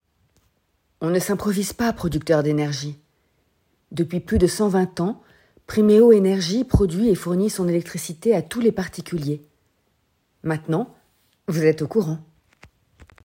30 - 30 ans - Mezzo-soprano
doublage, voix off